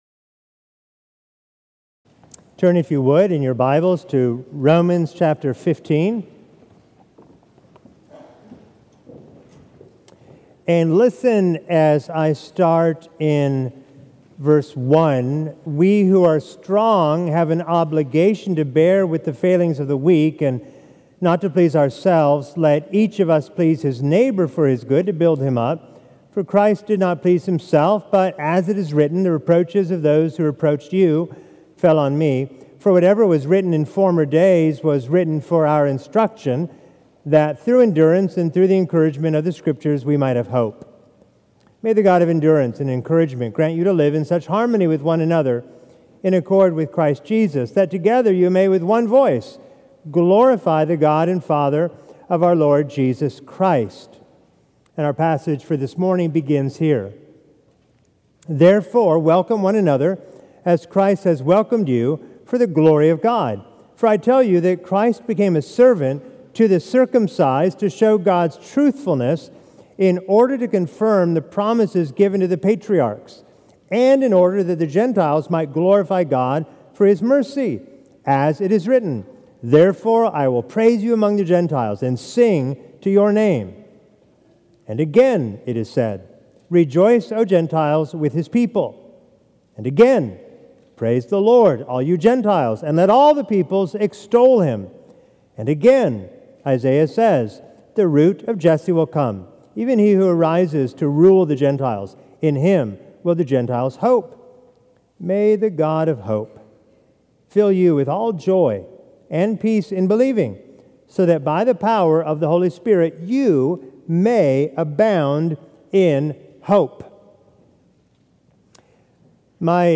850 Mt Vernon Hwy NW Sandy Springs, GA 30327 GET DIRECTIONS